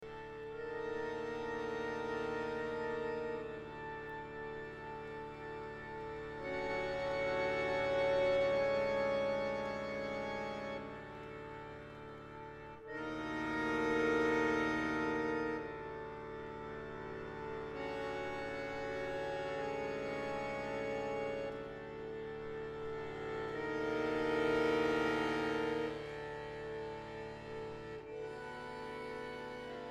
for bayan and symphonic orchestra